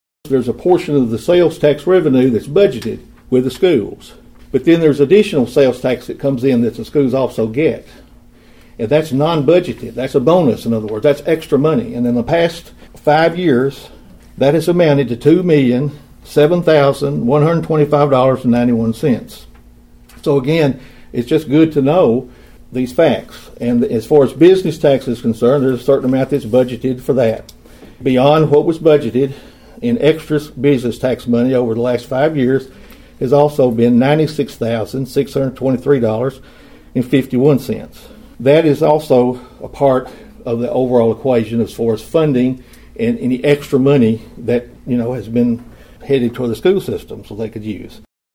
During his nearly 17-minute address to the Budget Committee last week, Mayor Carr said County Commissioners have exceeded their maintenance of effort for the schools.(AUDIO)